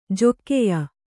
♪ jokkeya